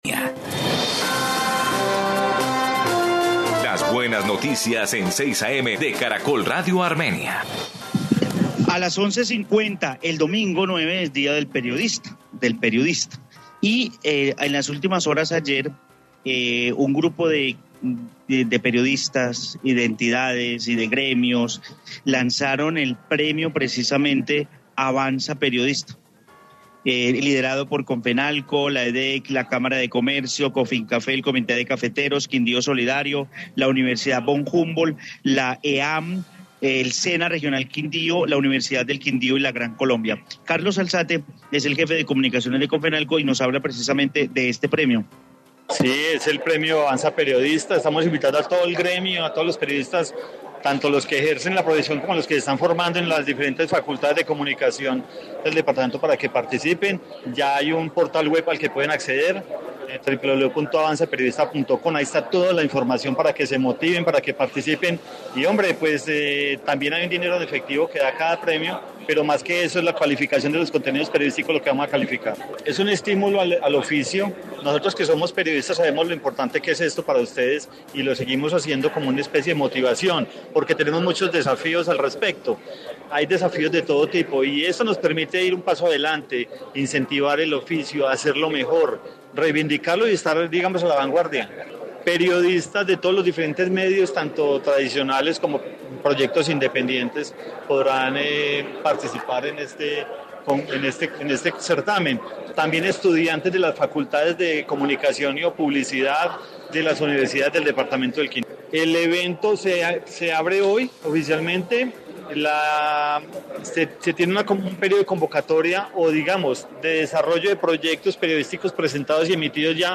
Informe Premio Avanza Periodista